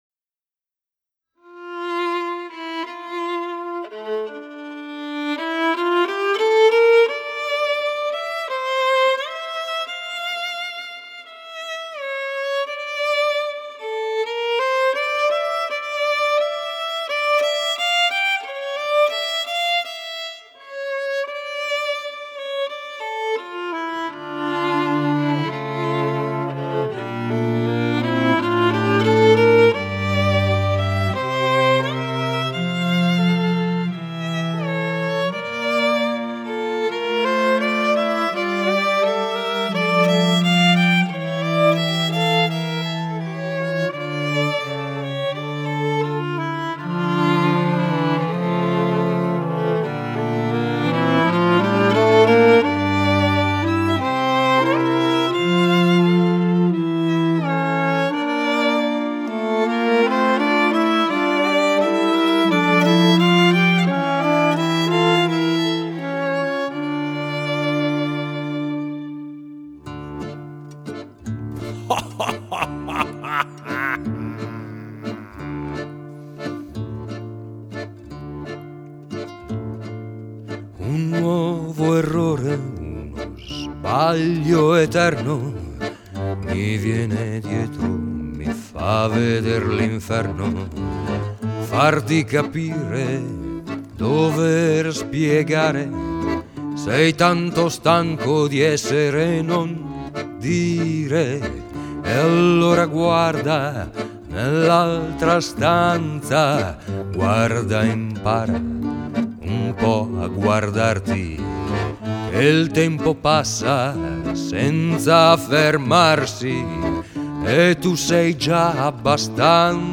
GenereWorld Music